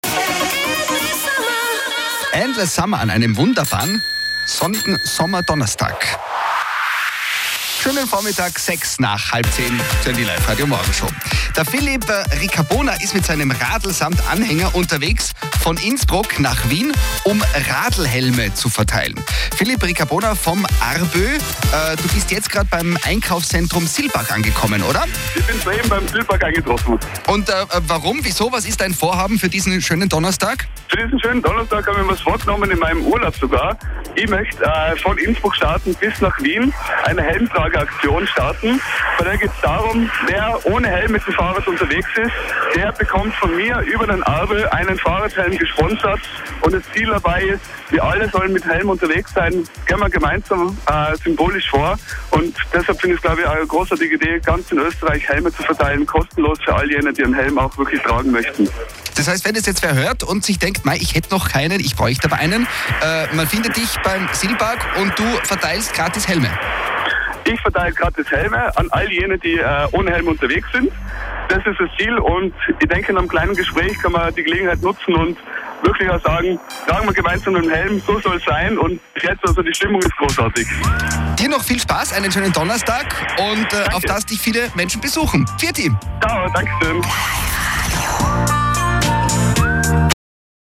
23_Medienbericht_Radio_Tirol.mp3